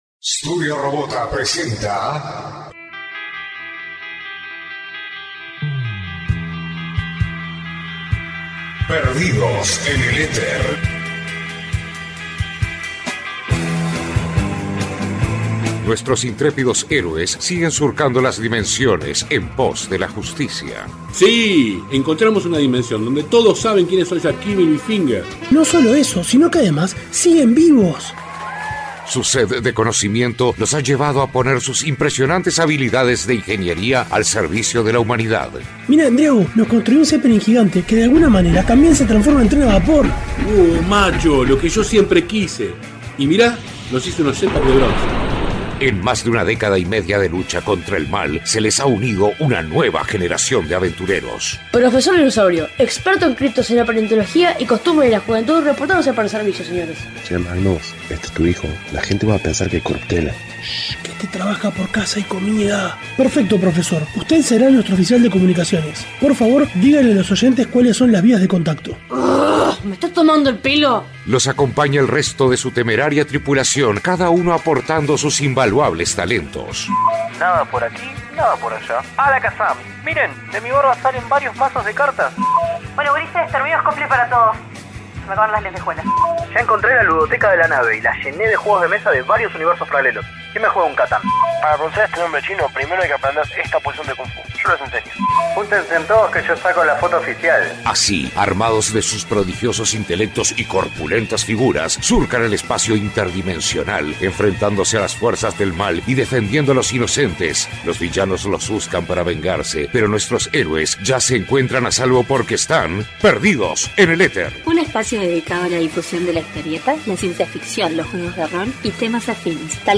Desde 2003, Perdidos En El Éter ha sido un programa radial (por momentos online, y por momentos en radio tradicional) dedicado a los comics y temas afines, como la ciencia ficción, los juegos de rol y la animación. Todos sazonado con buena musica y con mucho humor.